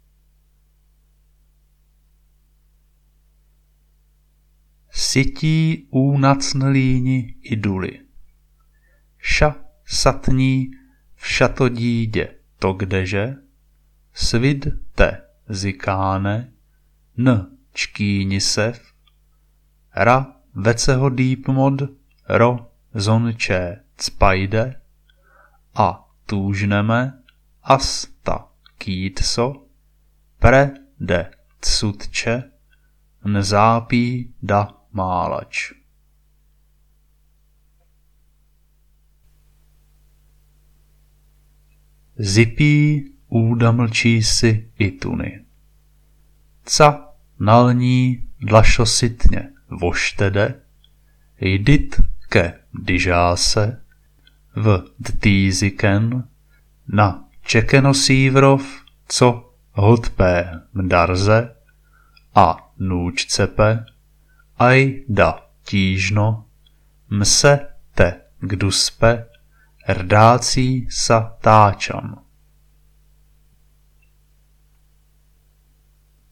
Šifra 3 se přehrávala z reproduktorů v nekonečné smyčce u pilíře Hitlerovy dálnice.
Mluvený projev zní jako smysluplný český text, jen nějak zpotvořený. Střídají se dvě verze znějící velmi podobně, pravděpodobně jde o týž text, pouze zdeformovaný jinak – a je-li šifra logická, tak jsou ty dvě verze nějakým způsobem komplementární.
Při podrobnějším prozkoumání, co mají projevy společného navzájem a s očekávaným začátkem tajenky, zjistíme, že samohlásky se zachovaly, ale něco se stalo souhláskám.